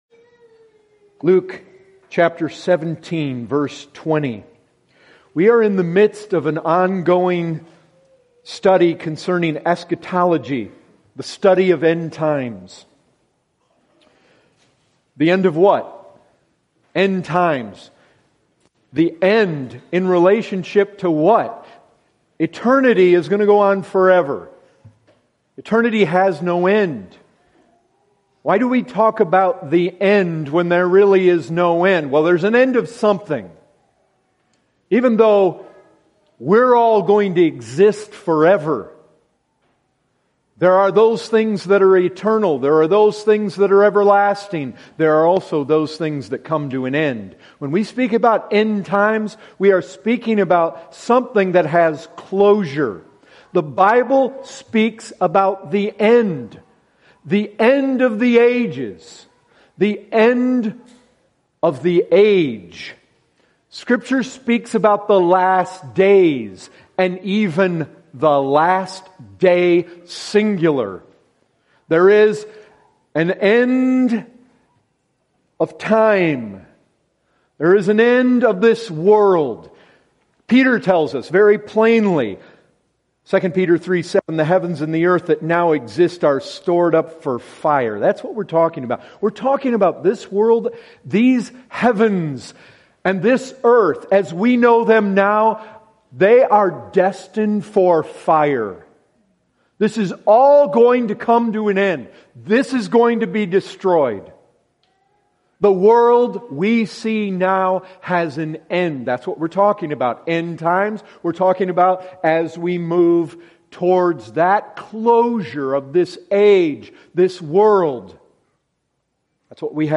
This sermon follows our Lord’s teaching on His Second Coming from Luke 17. Our Lord is very clear that His coming is going to be public, spectacular and terrifying.